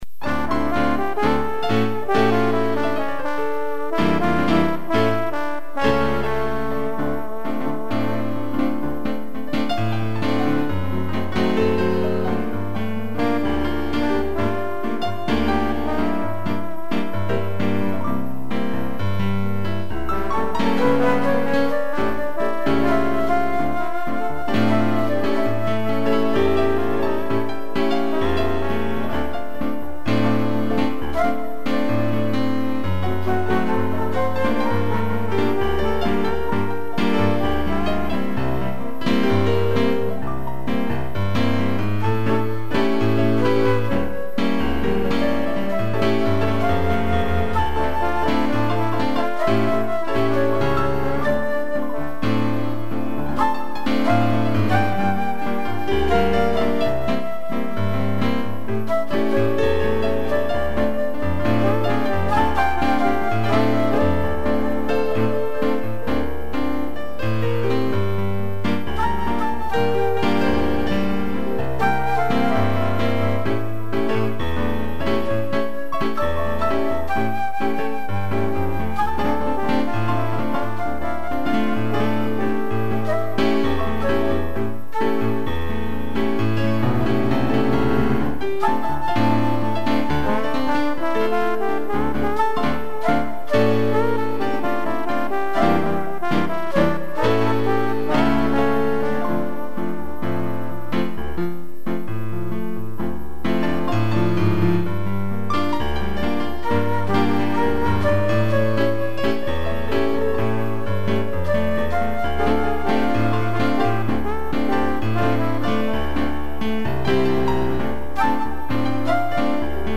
2 pianos, flauta e trombone
instrumental